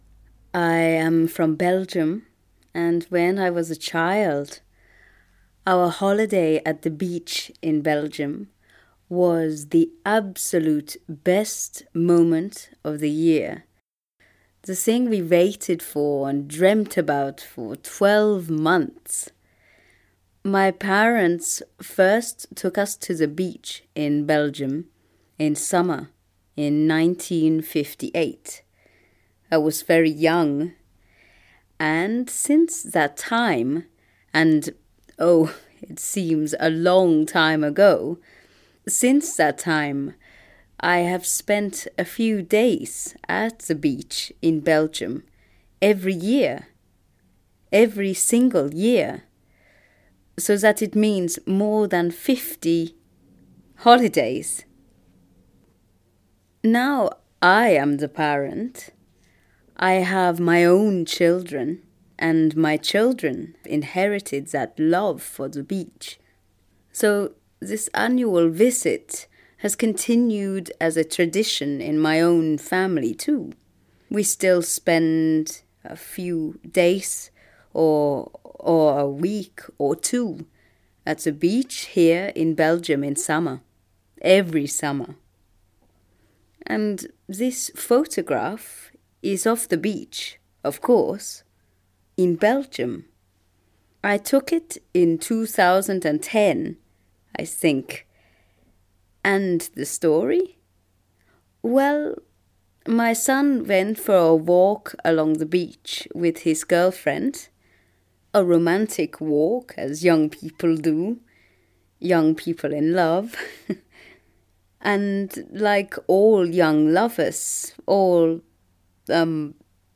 It focuses on a photograph of a heart drawn in the sand with two initials, and a recording of the photographer talking about the photo.